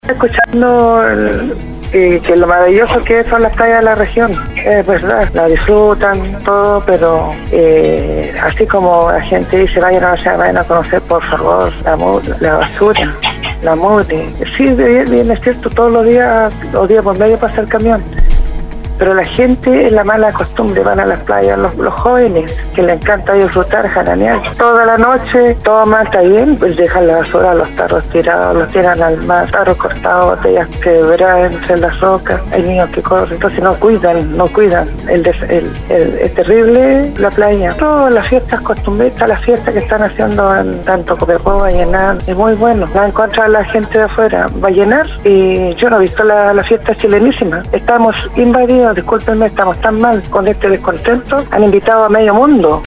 Para muchos de los que llamaron o enviaron mensajes al Programa Al día,  no está mal que se organicen ferias, festivales y que se disfrute de los lugares de recreación con los que cuenta Atacama, todo esto a pesar de la crisis por la que atraviesa el país, pero insistieron que se debe hacer respetando los espacios, dejando todo limpio y sobretodo disfrutar sanamente.